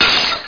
Amiga 8-bit Sampled Voice
brokengl.mp3